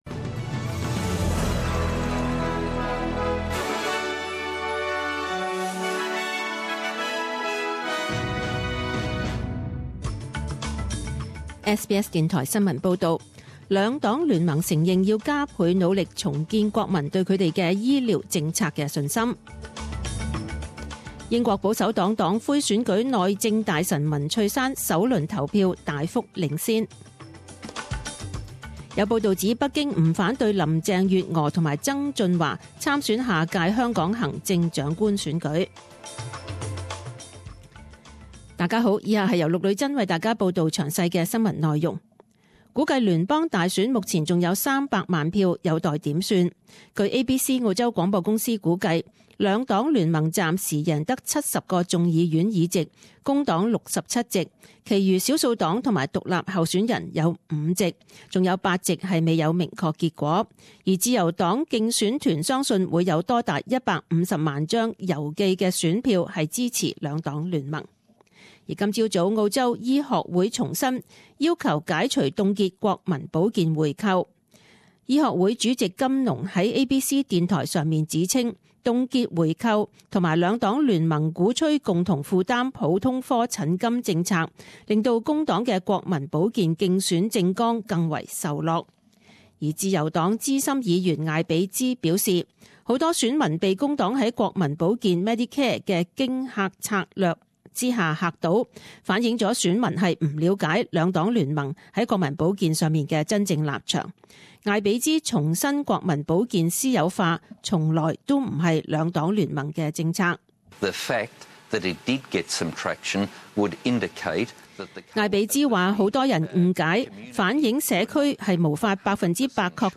十点钟新闻报导 （七月六日）